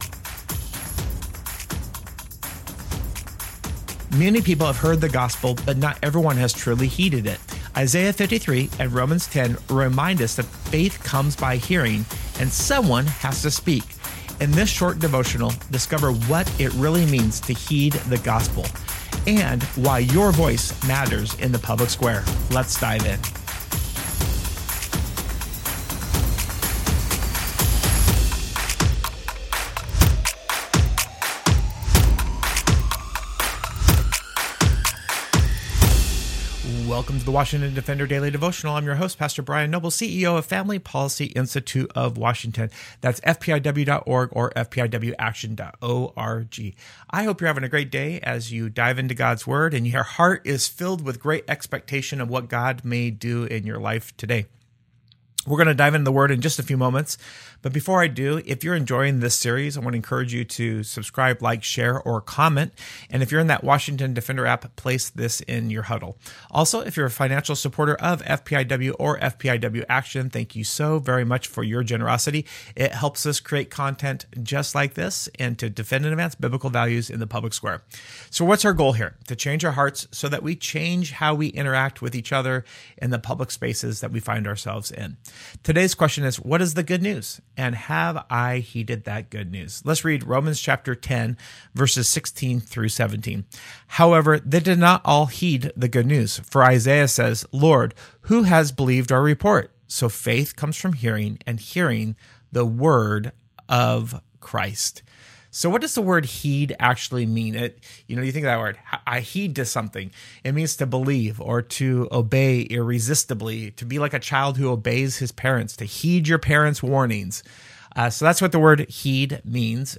Isaiah 53 and Romans 10 remind us that faith comes by hearing—and someone has to speak. In this short devotional, discover what it really means to heed the good news of Jesus, and why your voice matters in the public square today.